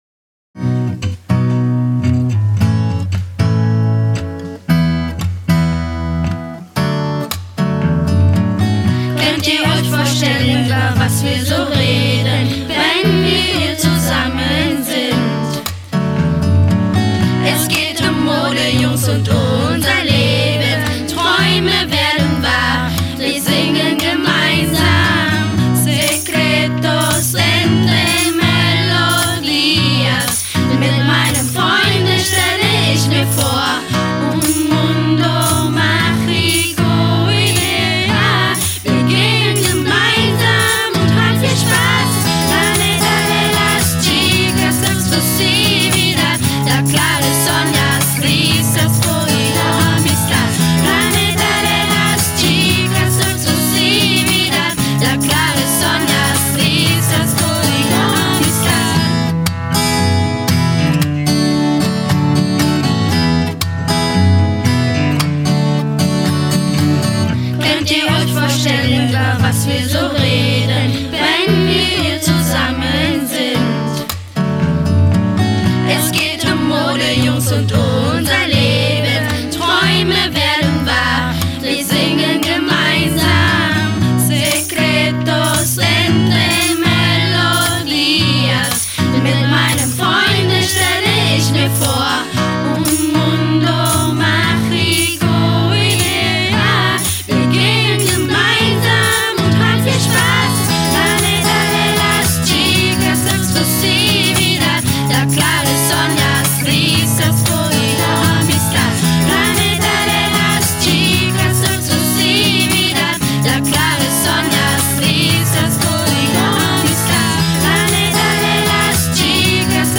Die CD Mädchemelodien haben die 11 Projektteilnehmerinnen zwischen 6 und 15 Jahren an zwei Novemberwochenenden im girlspace und in der GOT Elsassstraße aufgenommen.